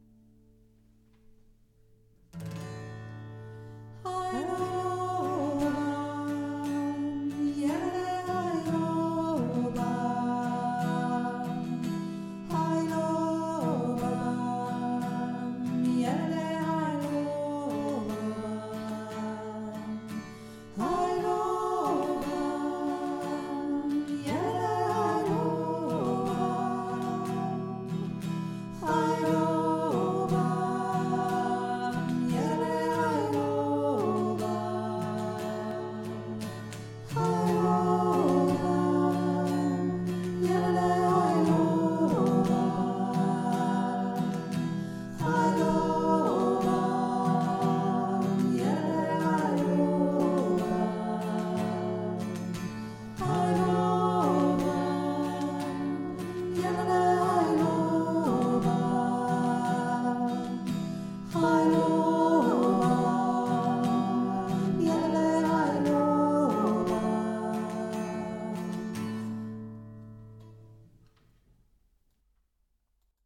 Heilobam (afrikanisches Lied - alles st vergänglich)